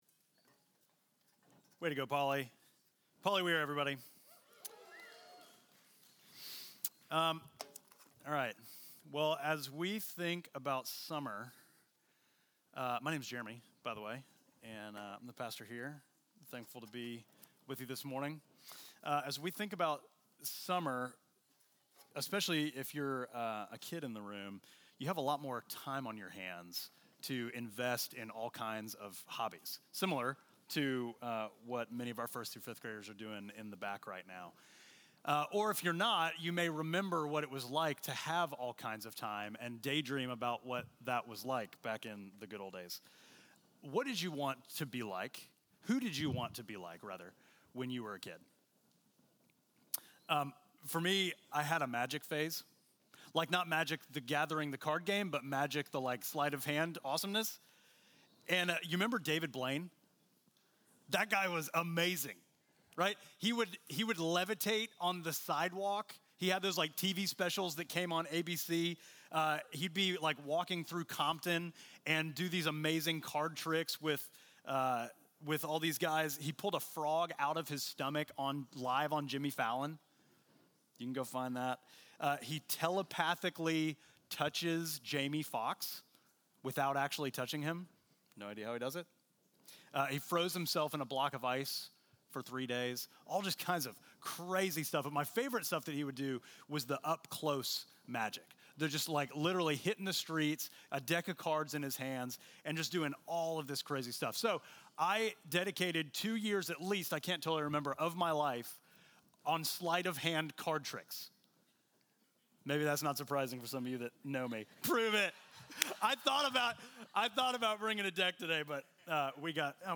Midtown Fellowship Crieve Hall Sermons The Great Commission Jun 02 2024 | 00:37:49 1x 00:00 / 00:37:49 Subscribe Share Apple Podcasts Spotify Overcast RSS Feed Share Link Embed